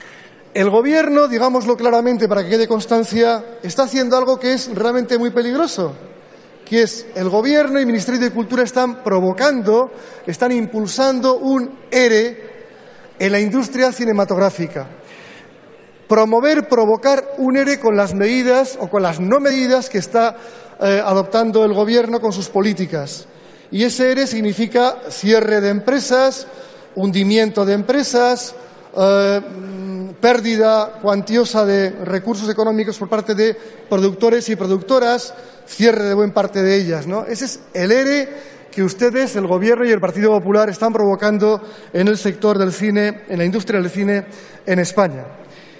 Fragmento de la intervención de Odón Elorza en apoyo a la moción del Grupo Parlamentario Catalán (Convergència i Unió), sobre la necesidad de adoptar políticas ambiciosas de apoyo a la actividad del sector cinematográfico. 16/09/14